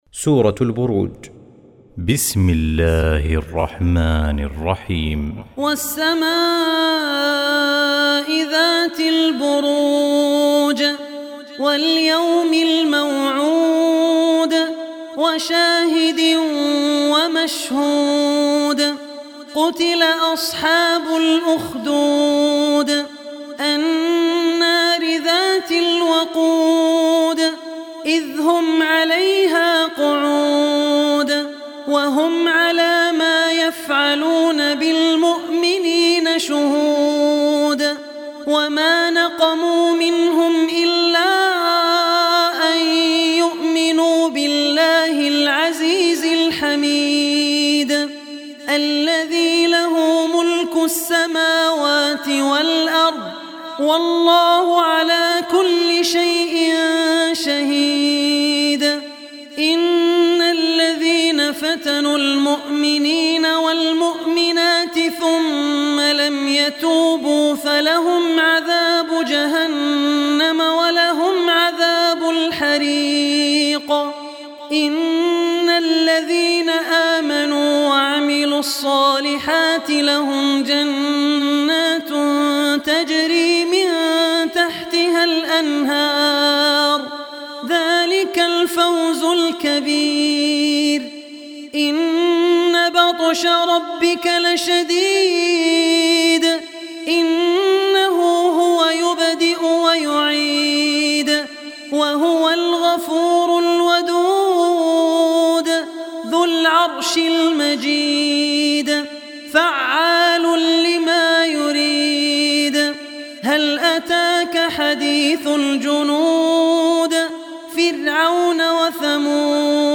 Online Recitation